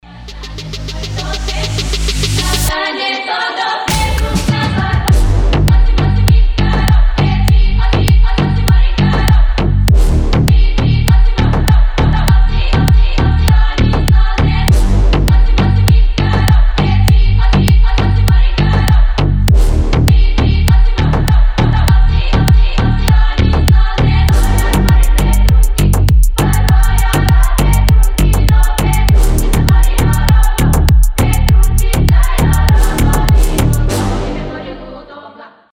• Качество: 320, Stereo
deep house
басы
G-House
хор
ремиксы